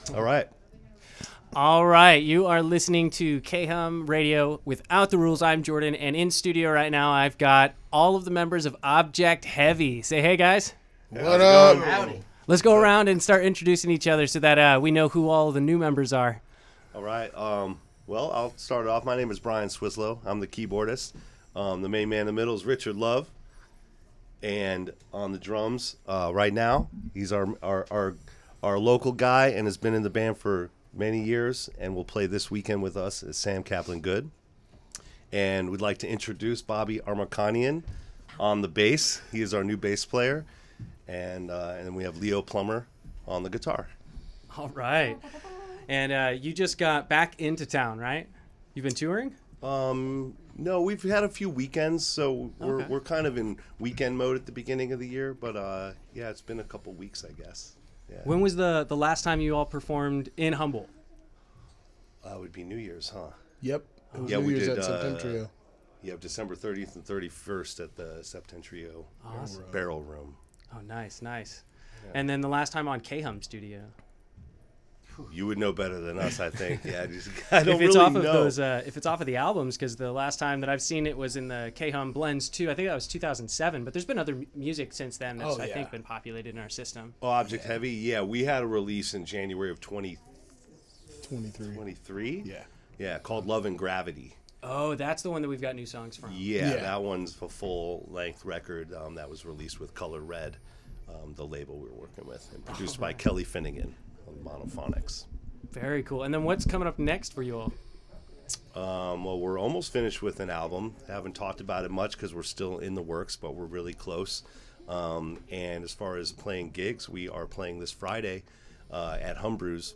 Object+Heavy+Interview+Audio.mp3